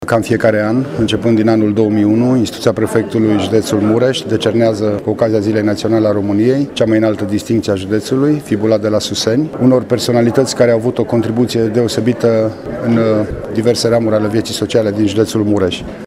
Ceremonia de premiere a fost organizată în Sala de Oglinzi a Palatului Culturii din Tîrgu-Mureș.
Distincțiile se acordă în fiecare an de Ziua Națională, spune prefectul județului Mureș, Lucian Goga.